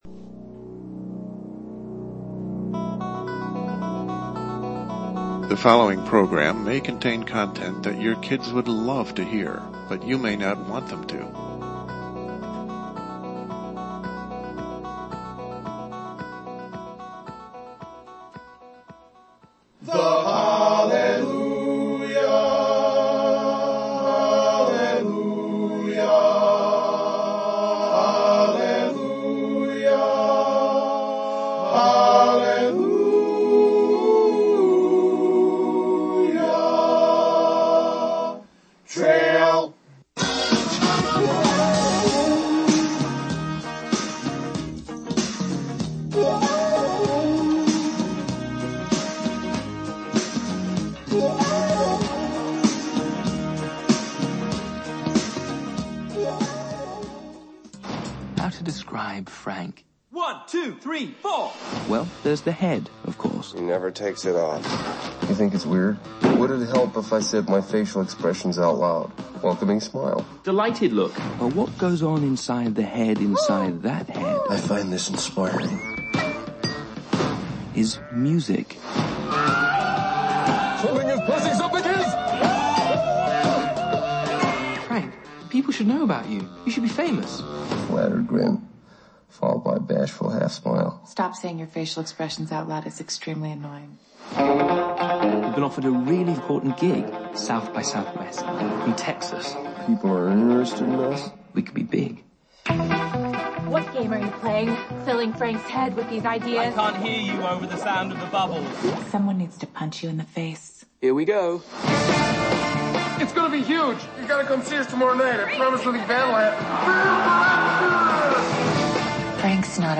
Studio maintenance done, we return live and catch up on a number of subjects that remain current after the break.